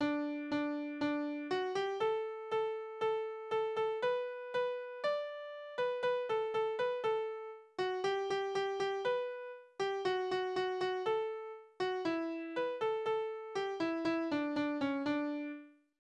Wiegenlieder: So fahren wir
Tonart: D-Dur
Taktart: 4/4
Tonumfang: Oktave